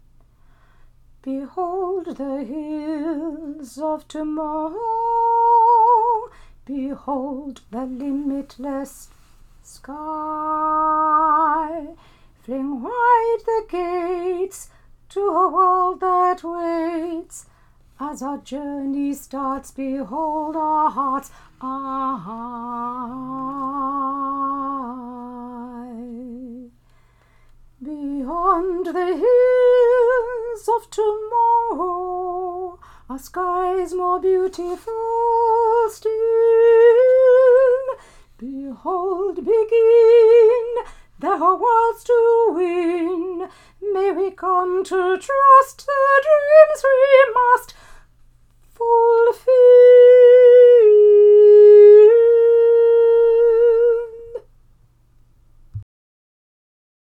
Sop 2